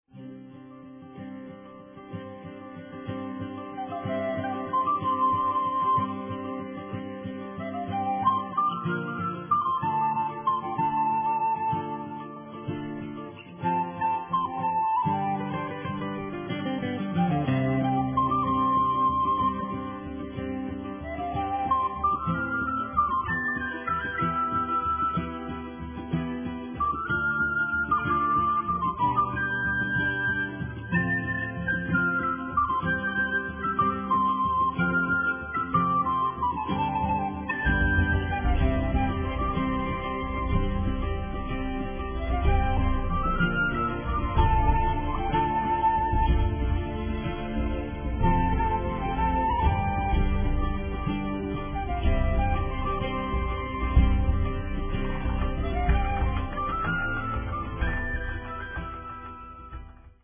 Hintergrundmusik für die Unterkunft